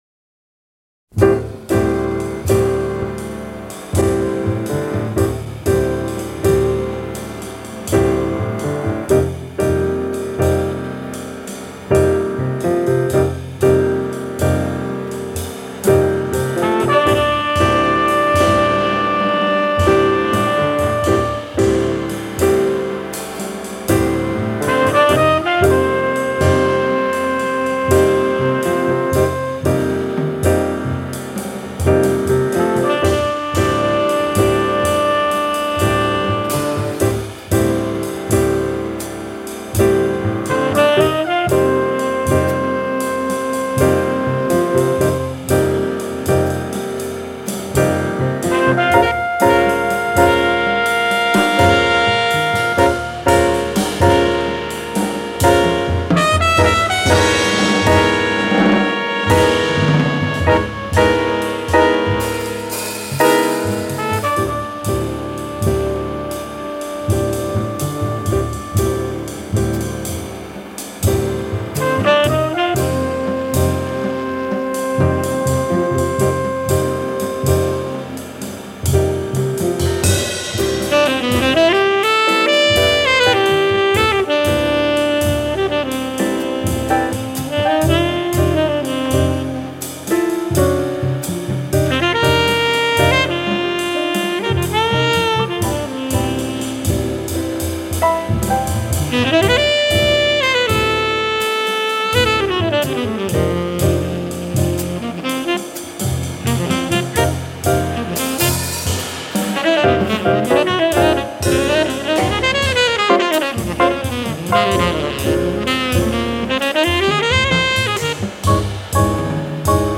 played by the composer and a stellar quintet